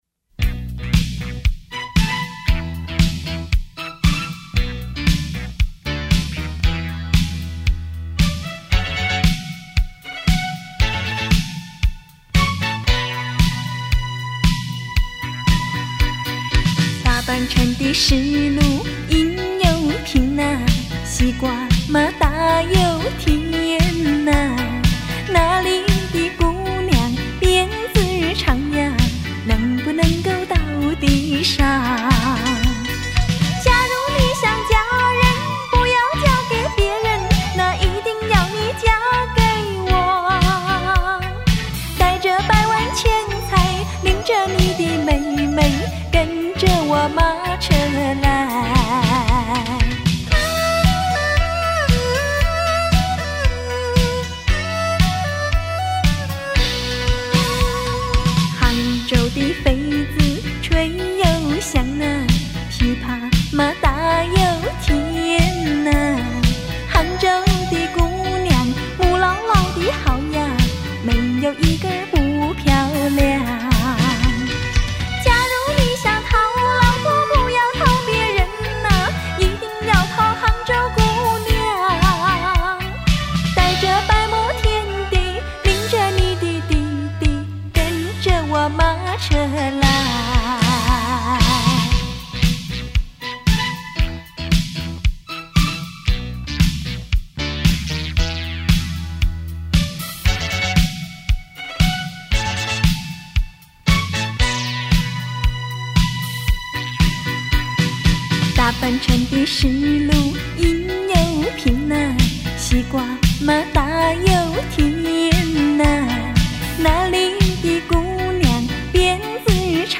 古典的优雅情兴与现代的浪漫心融合成一种款款深情的悸动